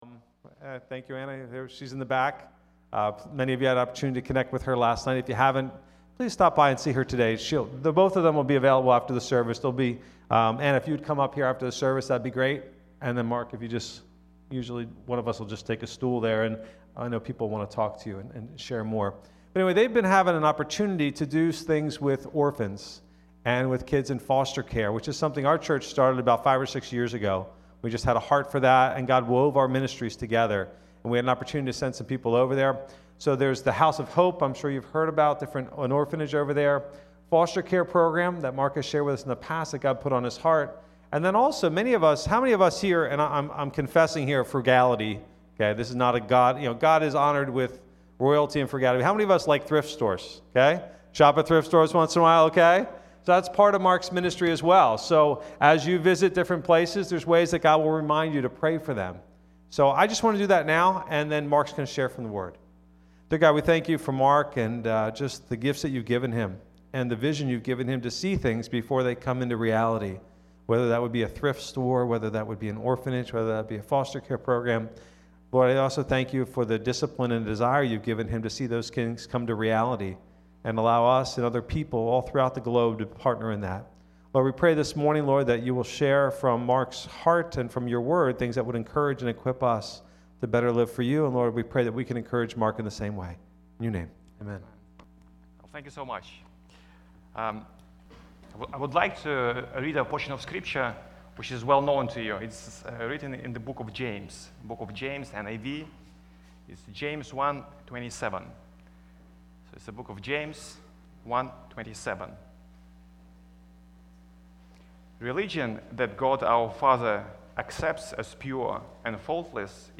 Mission Sunday 2011